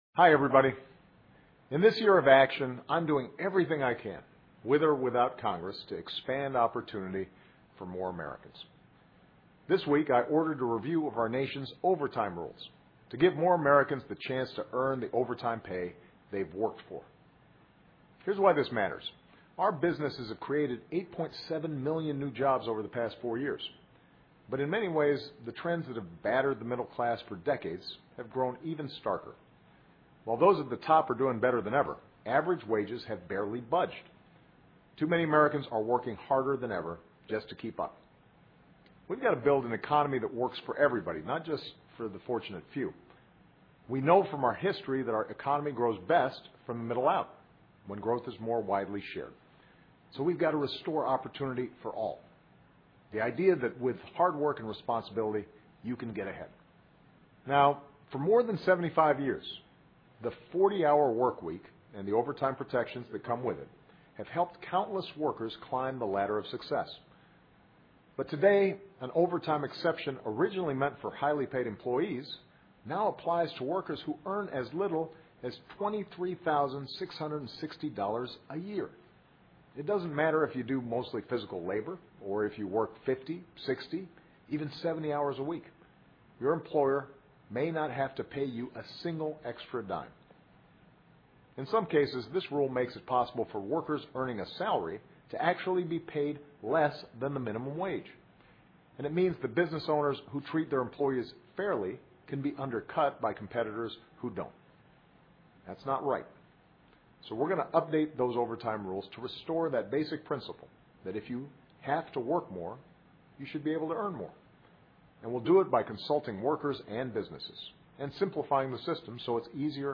奥巴马每周电视讲话：总统呼吁加班应有报酬 勤劳应有回报 听力文件下载—在线英语听力室